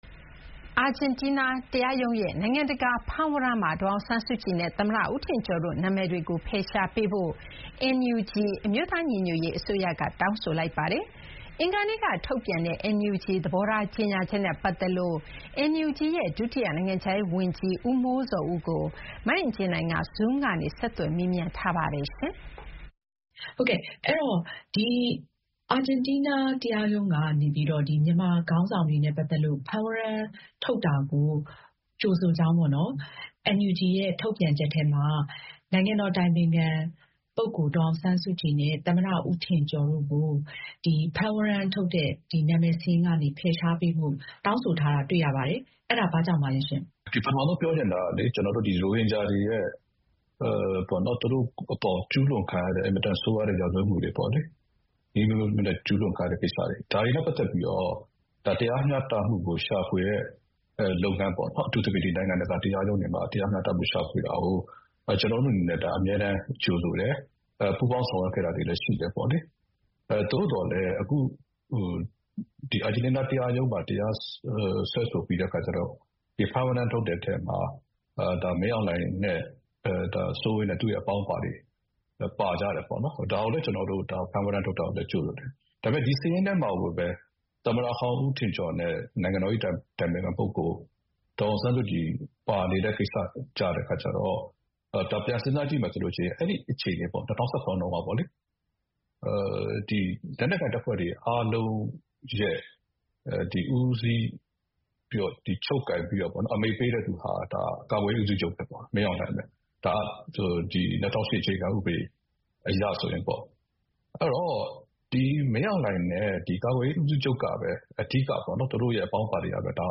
Zoom ကနေ ဆက်သွယ် မေးမြန်းထားပါတယ်။